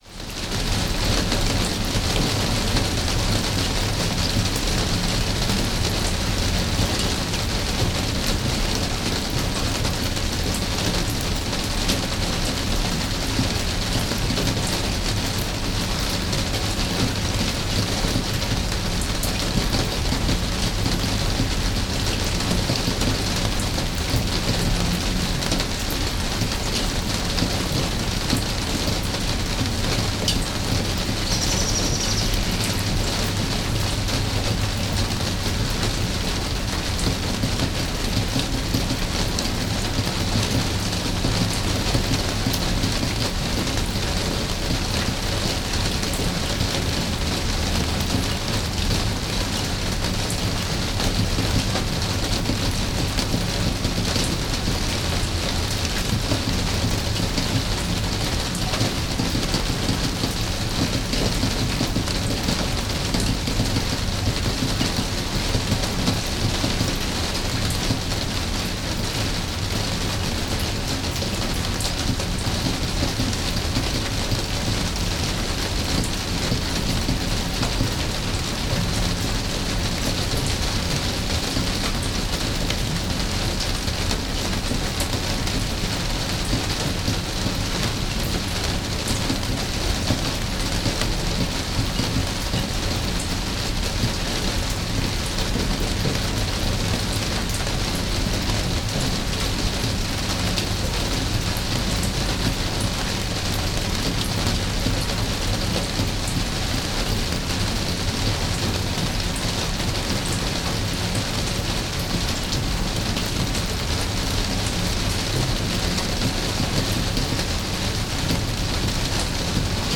Rain Falling On The Greenhouse
Ambience Ambient ASMR Atmosphere Atmospheric Audio Background Calm sound effect free sound royalty free Tiktok Trends